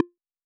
Down.wav « Normal_Mode « Resources - auditory.vim - vim interface sounds